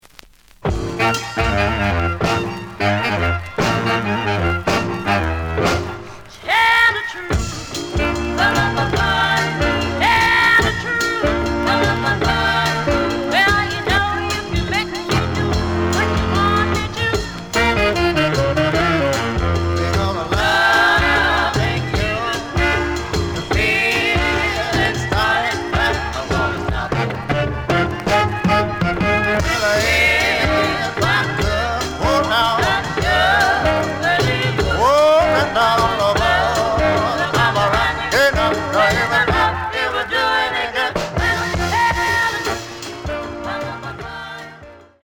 The audio sample is recorded from the actual item.
●Genre: Soul, 60's Soul
Slight click noise on both sides due to a bubble.)